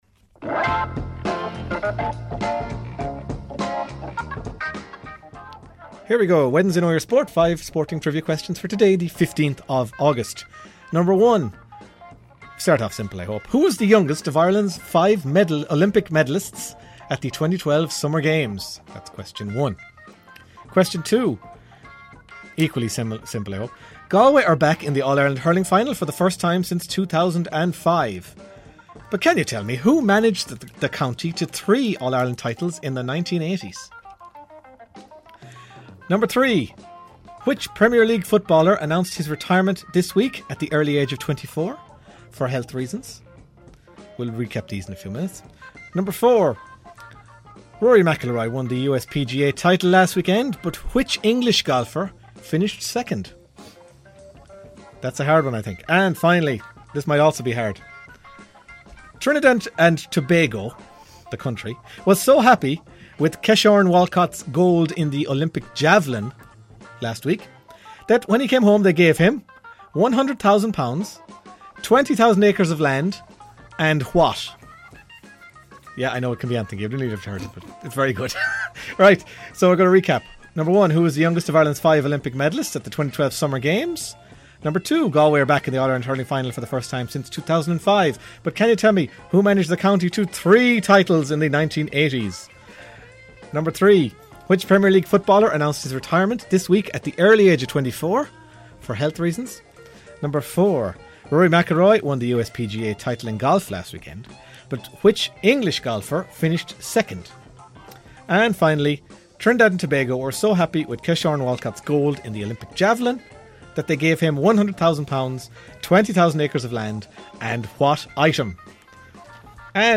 Five sports triva questions, as asked on 'Half-time Team Talk' on Claremorris Community Radio.